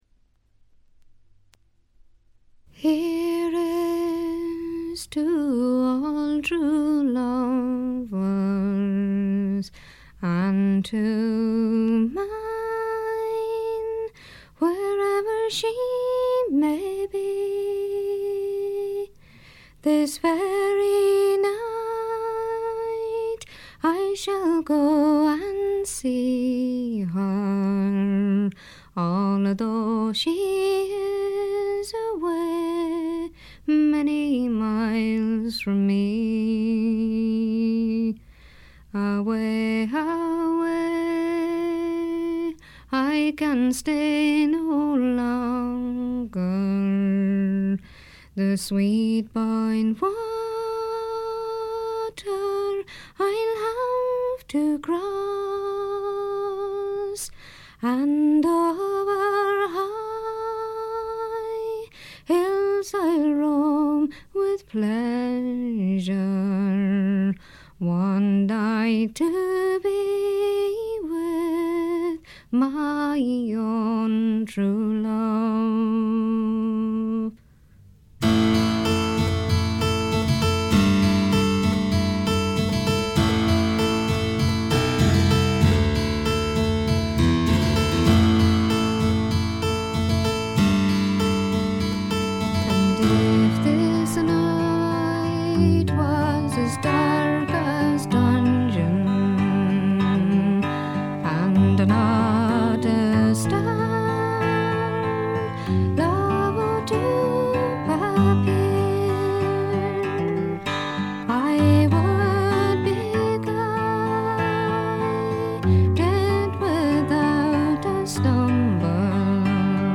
軽微なバックグラウンドノイズ程度。
時に可憐で可愛らしく、時に毅然とした厳しさを見せる表情豊かで味わい深いヴォーカルがまず最高です。
試聴曲は現品からの取り込み音源です。
※長尺のため途中まで...
vocals, harpsichord, bodhran